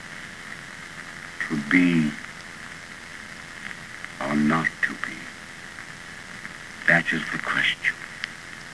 In 1925, the year of his acclaimed Hamlet at the Haymarket Theatre in London, John Barrymore recorded the "To be, or not to be" soliloquy for Famous Records.